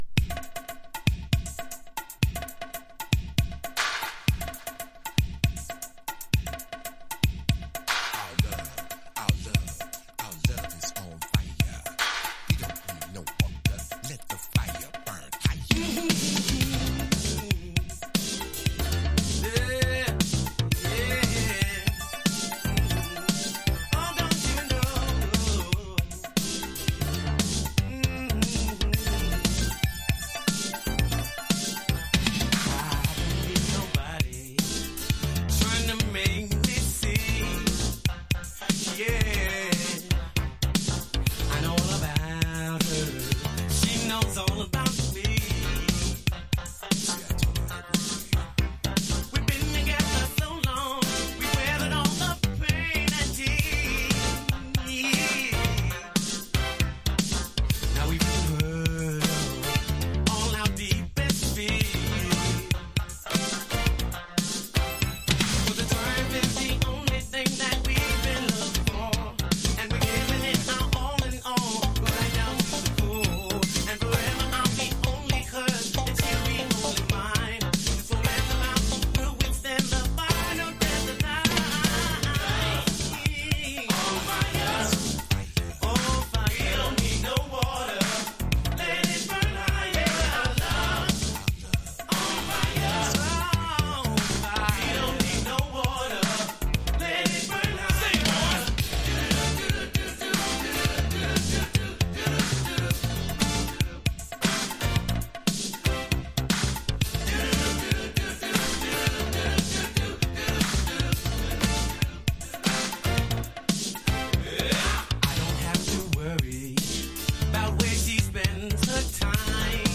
80年代感のあるトラックですがメロディーは超一流!!
FUNK / DEEP FUNK# DISCO